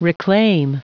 Prononciation du mot reclaim en anglais (fichier audio)
Prononciation du mot : reclaim